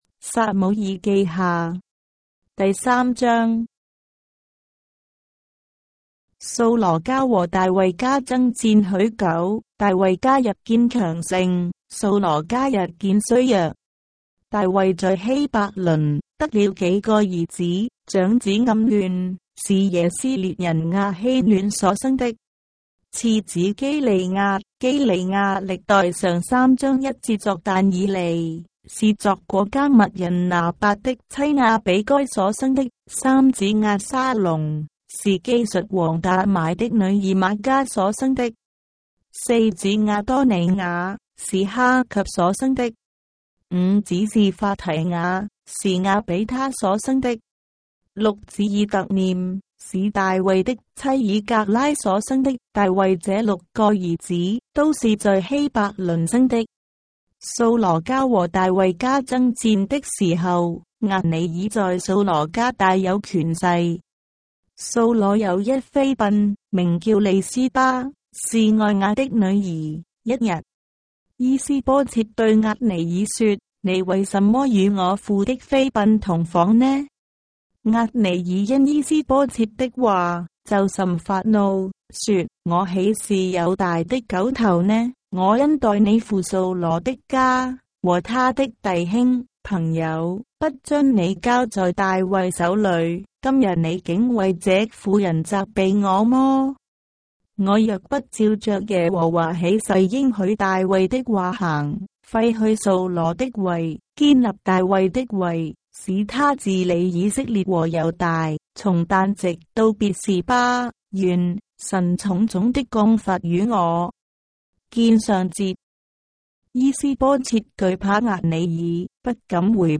章的聖經在中國的語言，音頻旁白- 2 Samuel, chapter 3 of the Holy Bible in Traditional Chinese